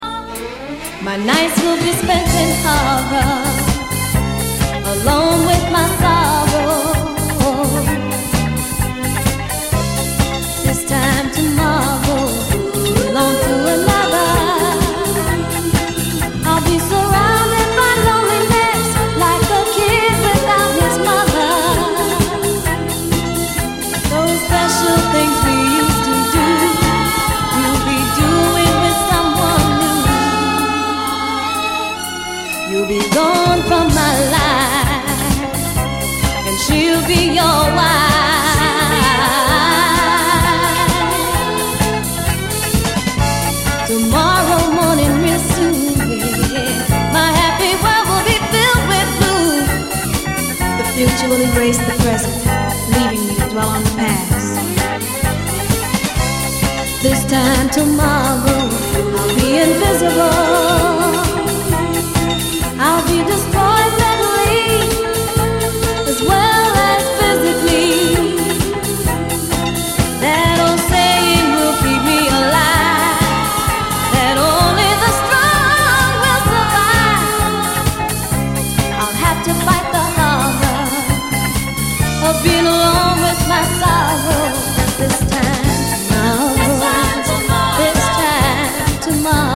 】1977年リリースのオリジナルは4,5万は下らない激レア・ファンキー・ソウル〜ディスコ・アルバムが正規復刻！